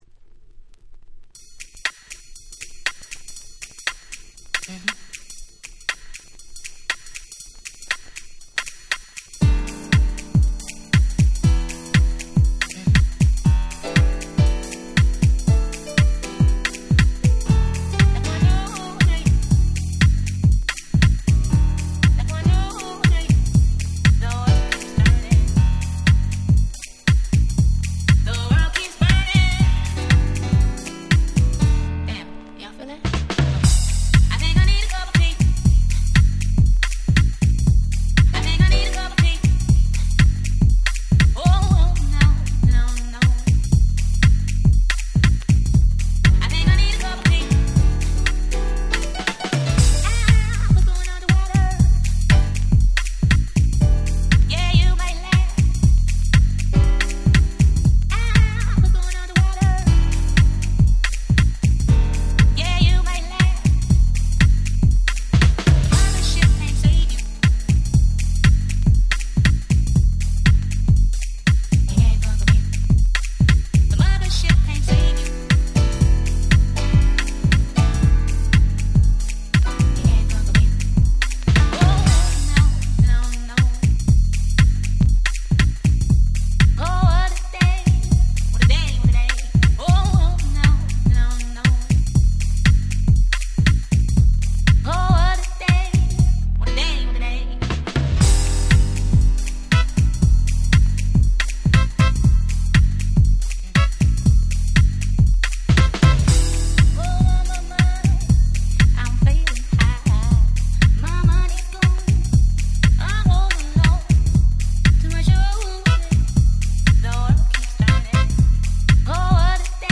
さり気なく入るピアノの音色だったり格好良すぎです！
90's ネオソウル ハウス House